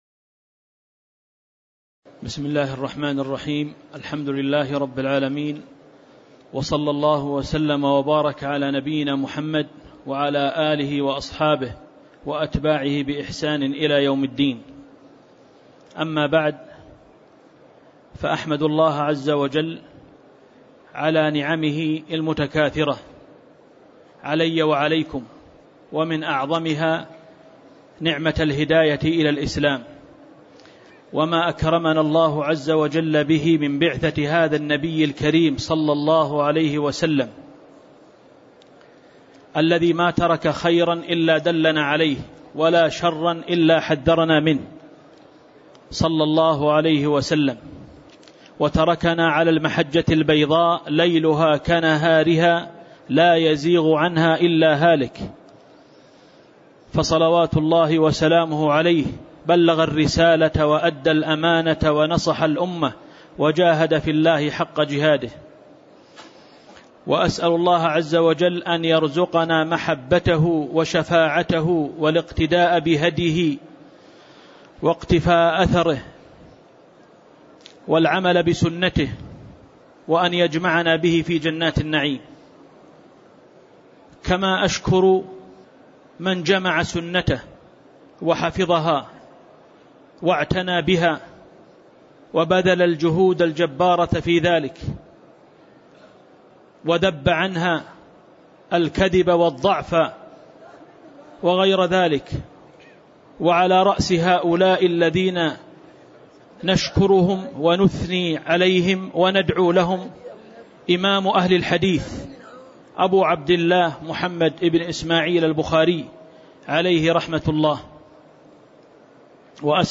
تاريخ النشر ١١ رجب ١٤٣٨ هـ المكان: المسجد النبوي الشيخ